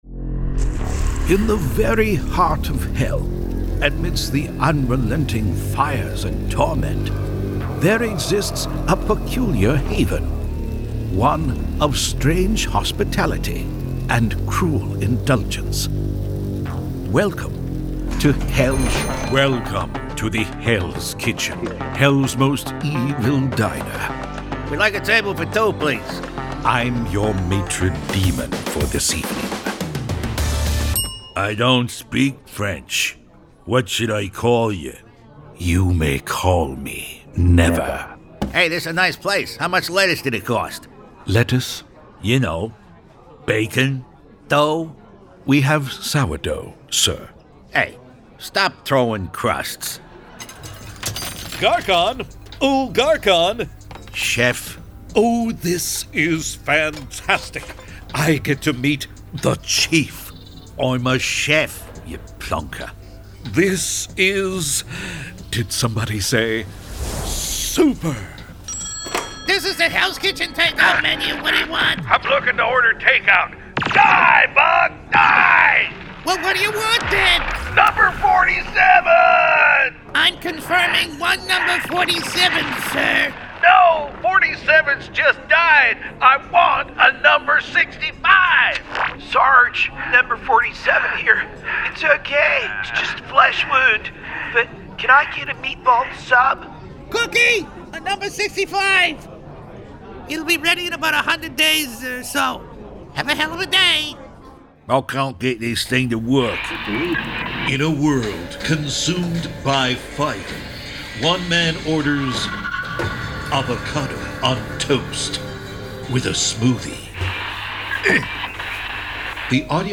Male
Adult (30-50), Older Sound (50+)
Conversational, believeable and occasionally trailer big, a deep strong voice for epic narrations, and a soft melodic voice for a fairytale.
Character / Cartoon
Latest Character Reel
All our voice actors have professional broadcast quality recording studios.